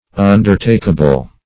\Un`der*tak"a*ble\